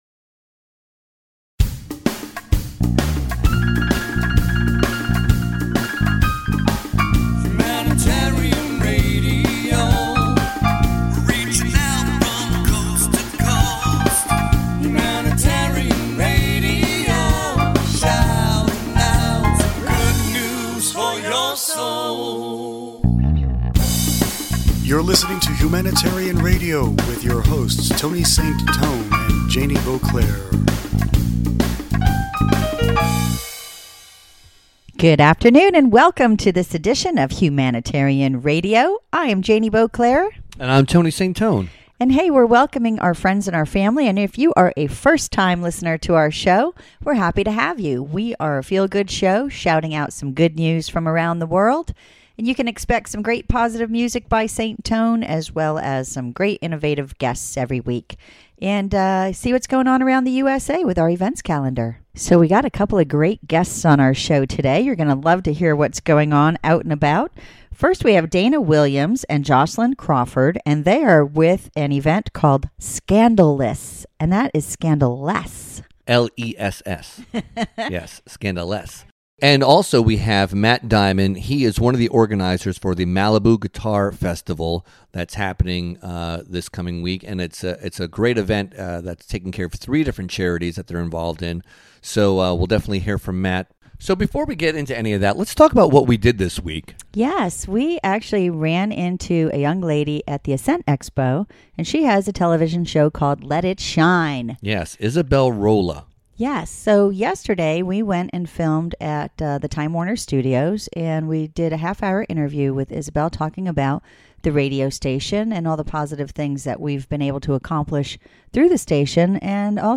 Entertainment Humanitarian Radio Interview